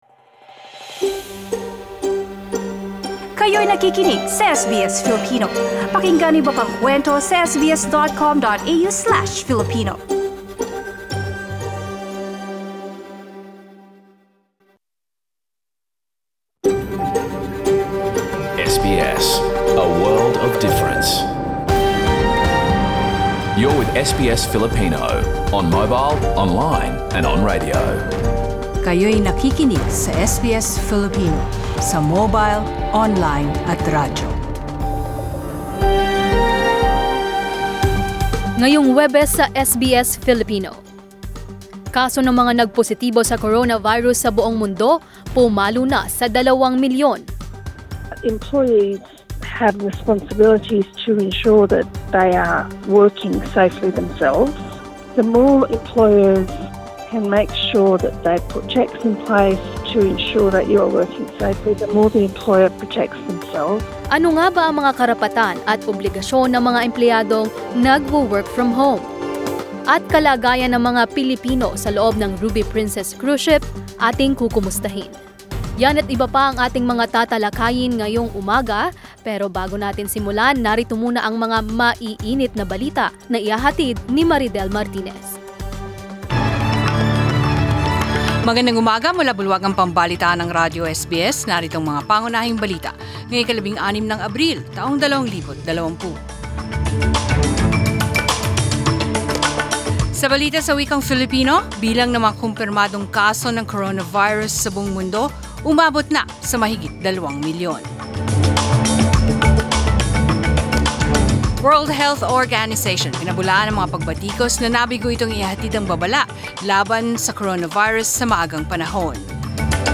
SBS News in Filipino, Thursday 16 April